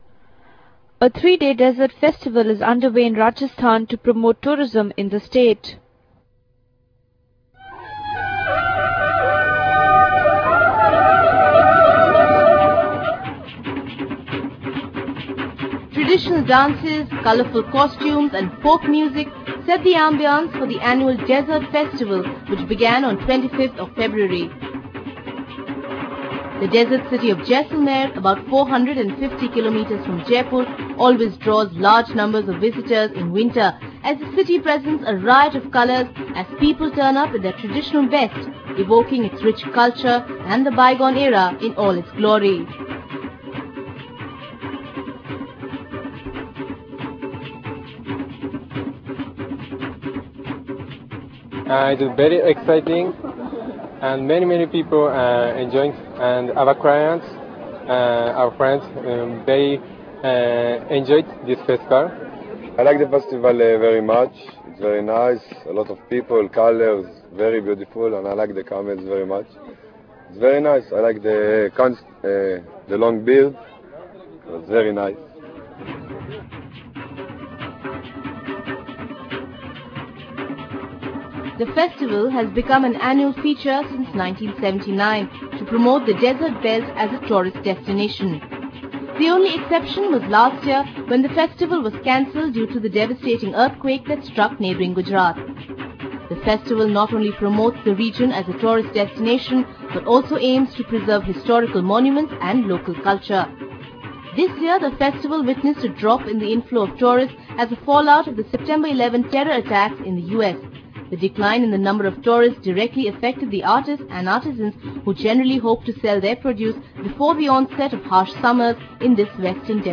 The Jaisalmer desert festival, an extravaganza of colour, music and festivity, concluded today.